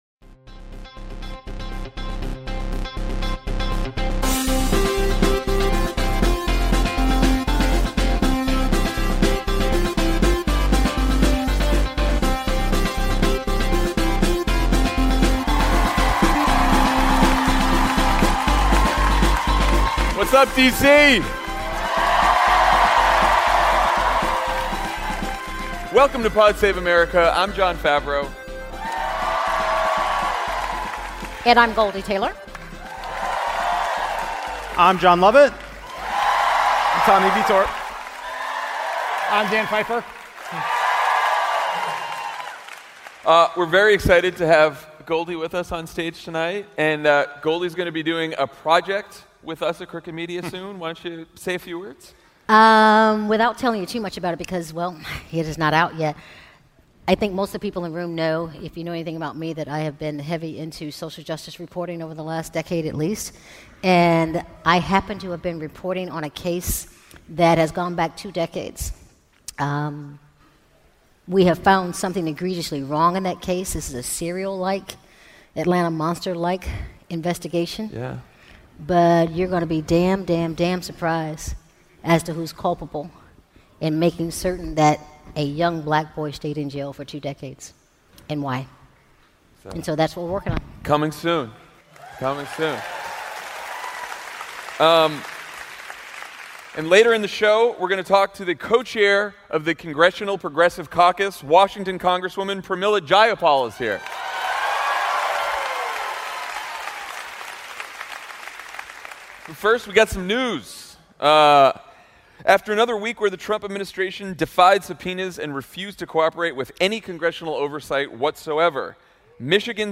Justin Amash becomes the first Republican Congressman to say that the President crossed the threshold for impeachment, and Joe Biden says that Democrats don’t want an angry candidate. Congresswoman Pramila Jayapal and Goldie Taylor join Jon, Jon, Tommy, and Dan on stage at the Anthem Theater in Washington, DC.